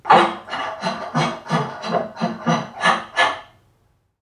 NPC_Creatures_Vocalisations_Robothead [81].wav